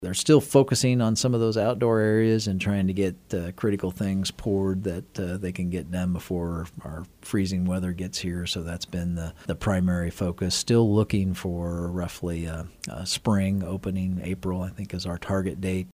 City Manager Ron Fehr provided a progress report during KMAN’s In Focus.